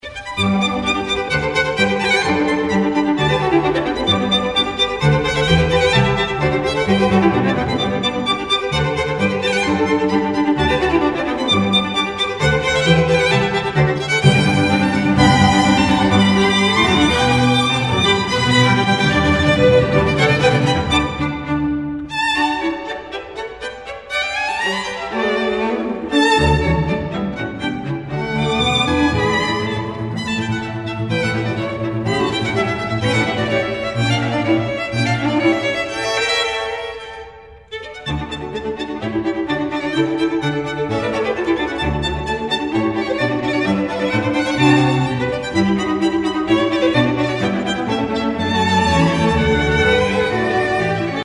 Категория: Классические | Дата: 09.12.2012|